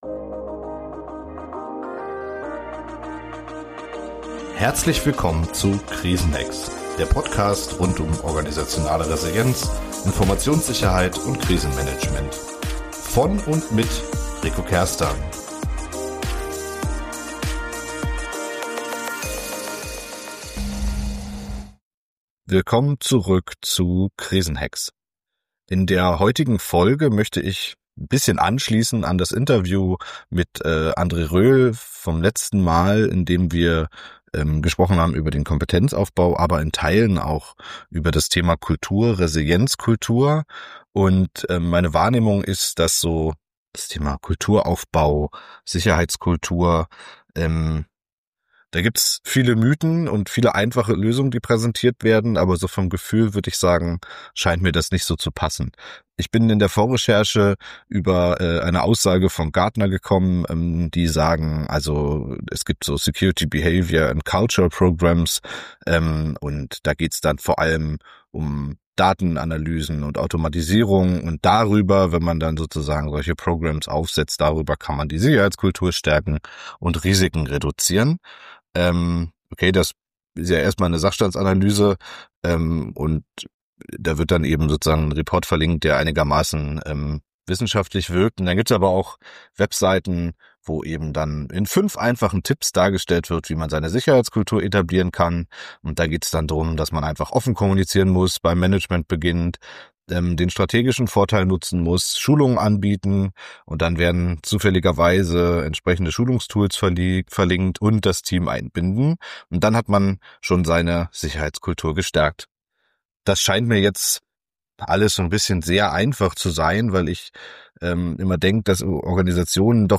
Das Gespräch beginnt mit der Frage, wie man Kultur überhaupt definieren kann – jenseits von Hochglanzbroschüren und Schlagworten wie „offene Fehlerkultur“.